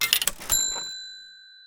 cha ching
buy cash coin register sell trade sound effect free sound royalty free Memes